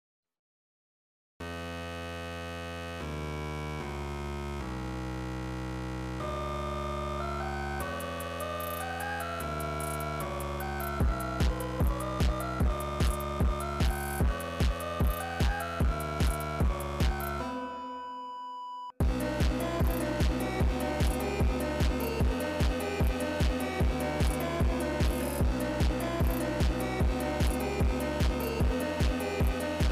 Жанр: Электроника / Русские